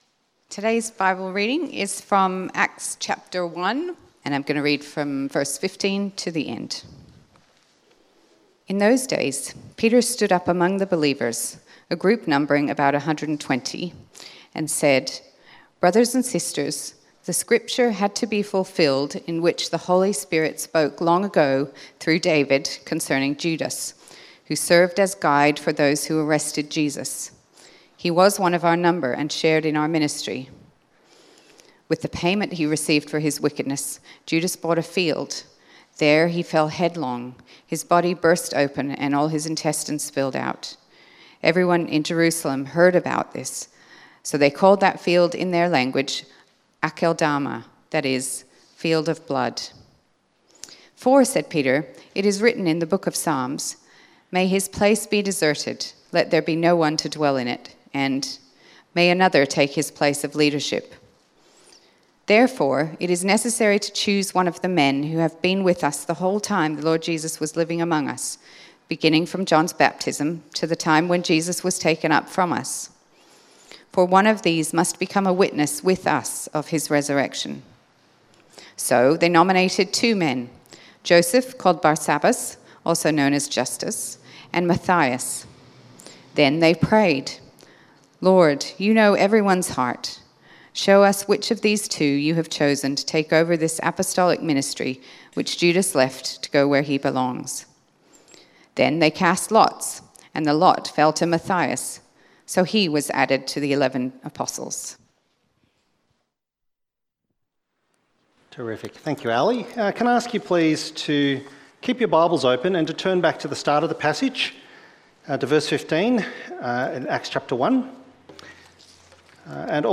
Great Prayers in the Bible Sermon outline